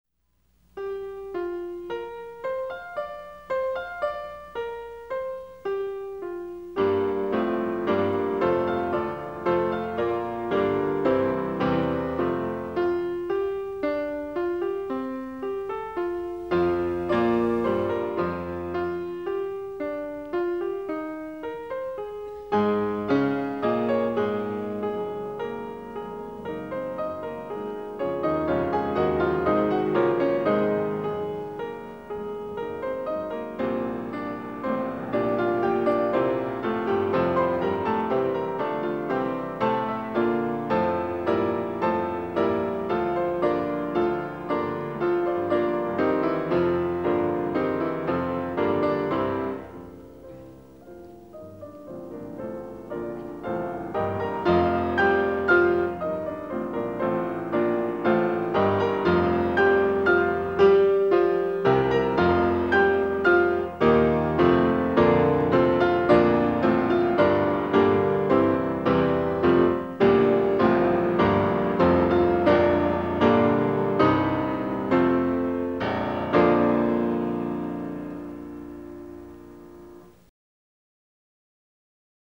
音效不是很好
钢琴和乐团象是在竞赛，又结合得谐调无间，浑然天成。